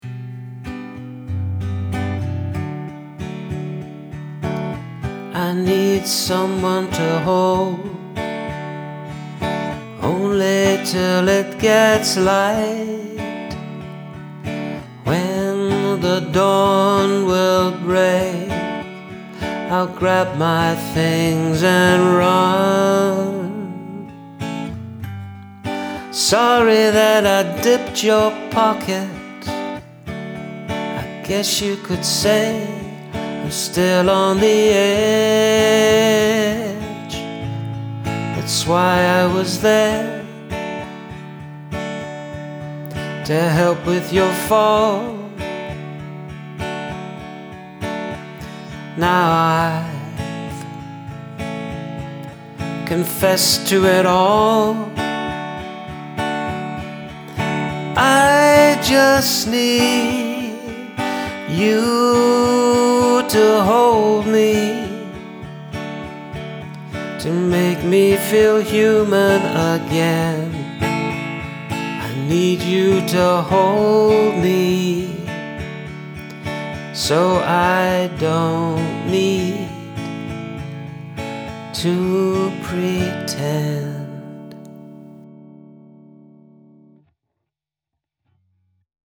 Very nice rhythm.
Love the vocal delivery of your lovely lyrics :)